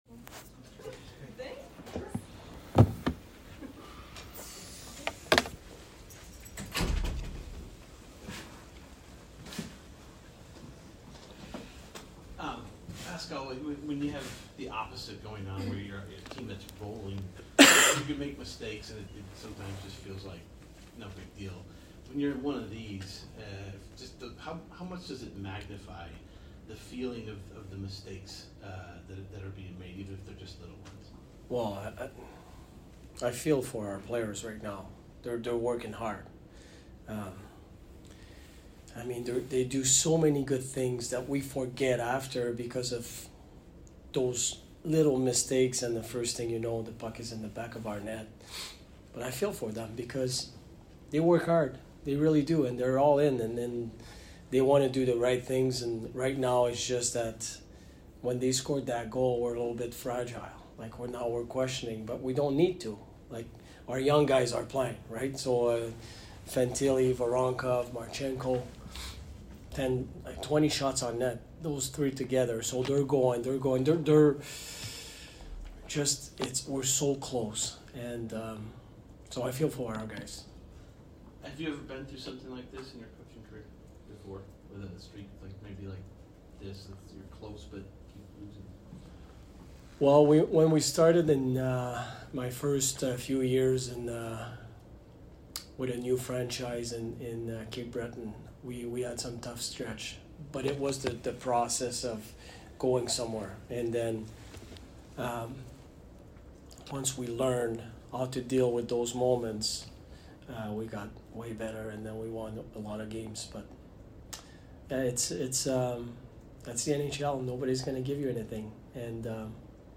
BLUE JACKETS POST-GAME AUDIO INTERVIEWS
HC Pascal Vincent | F Adam Fantilli | D Damon Severson | F Dmitri Voronkov